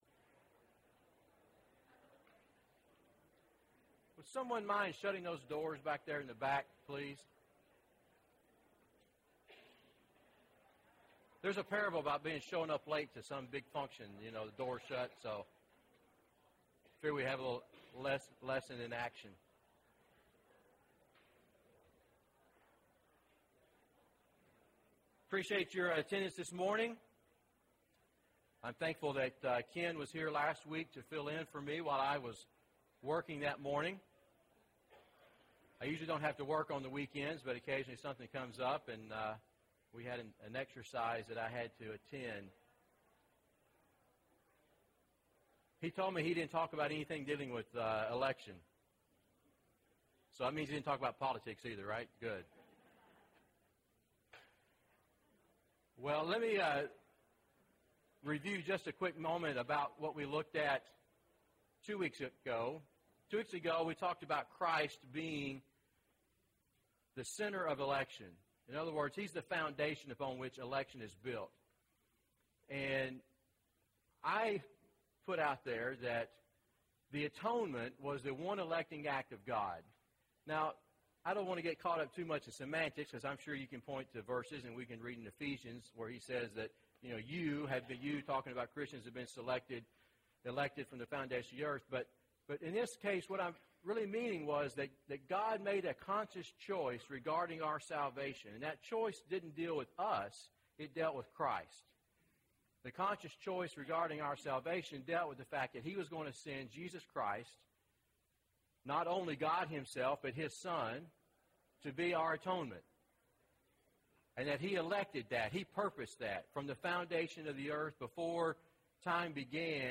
The Church, The Elected (10 of 13) – Bible Lesson Recording